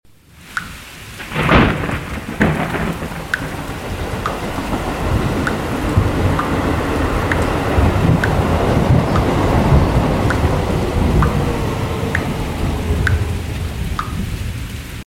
Hey everyone, I just dropped a 2-Hour City Storm Ambience video on my YouTube channel—distant thunder, steady rain, and subtle urban vibes perfect for studying or chilling.